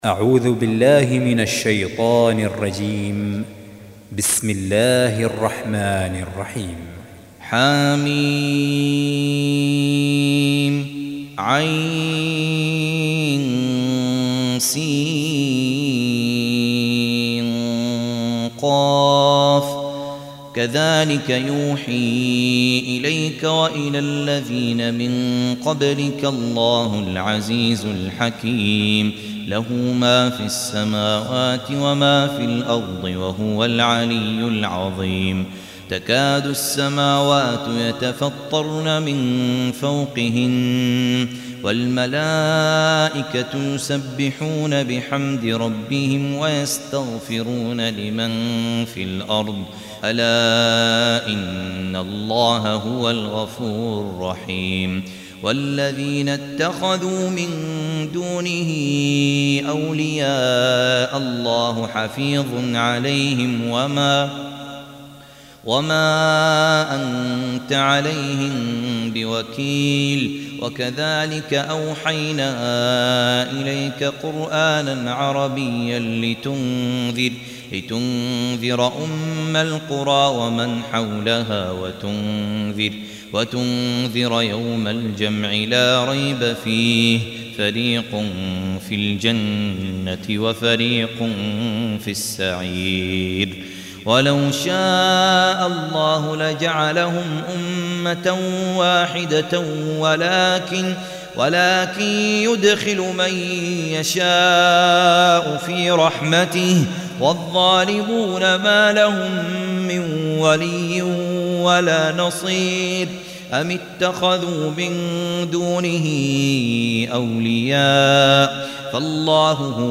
42. Surah Ash-Sh�ra سورة الشورى Audio Quran Tarteel Recitation
حفص عن عاصم Hafs for Assem
Surah Repeating تكرار السورة Download Surah حمّل السورة Reciting Murattalah Audio for 42. Surah Ash-Sh�ra سورة الشورى N.B *Surah Includes Al-Basmalah Reciters Sequents تتابع التلاوات Reciters Repeats تكرار التلاوات